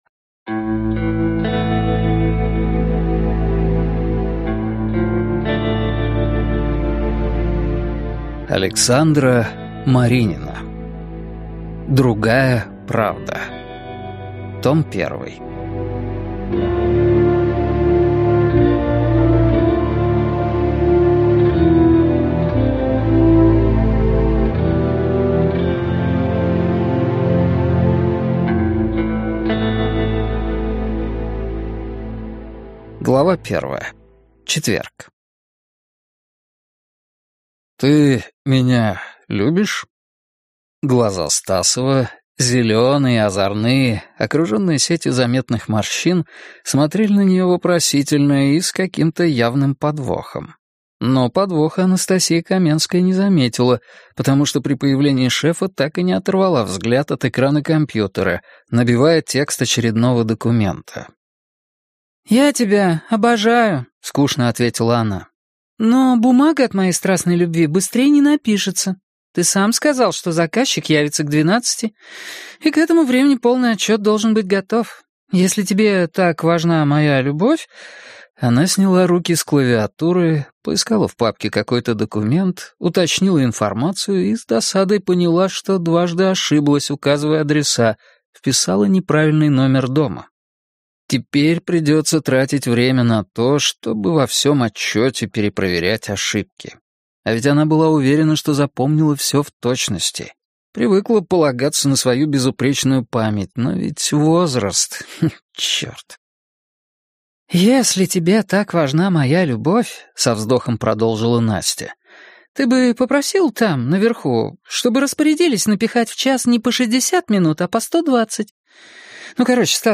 Аудиокнига Другая правда.